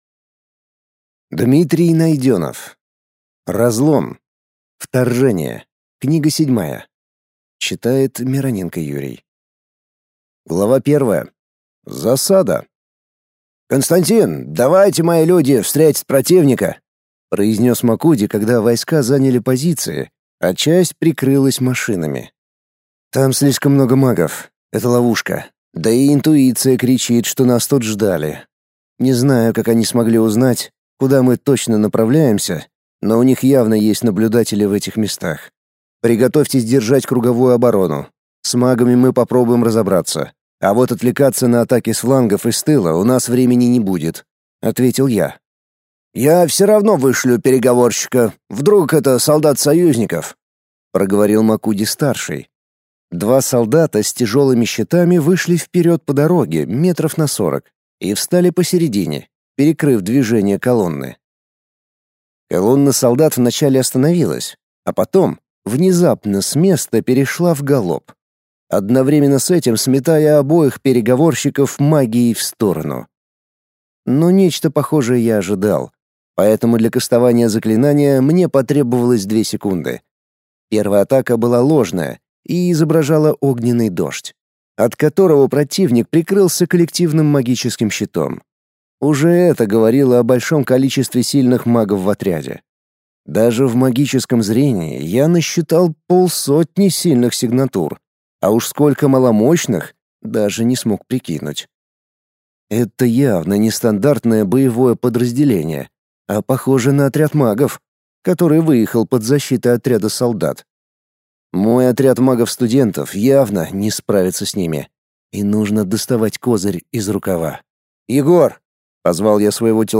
Аудиокнига Разлом. Вторжение. Книга седьмая | Библиотека аудиокниг